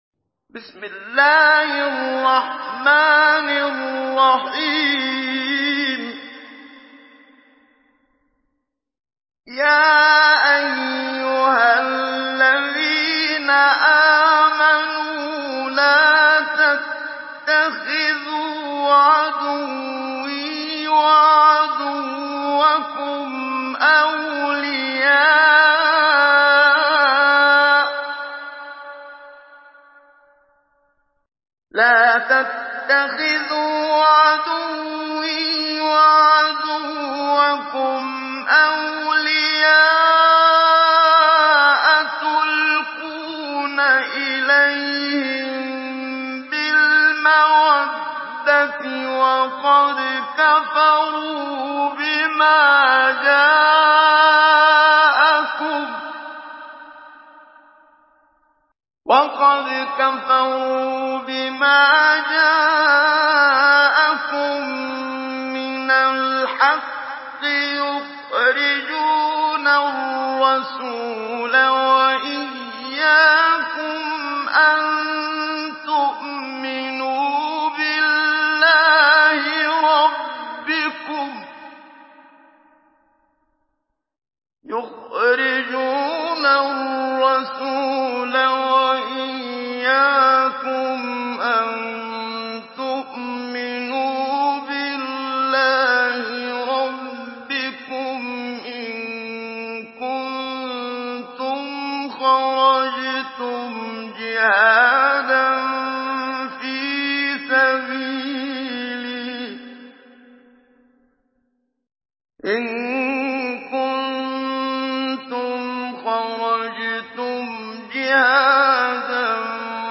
Surah الممتحنه MP3 by محمد صديق المنشاوي مجود in حفص عن عاصم narration.